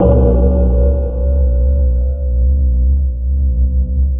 POWERBELL C2.wav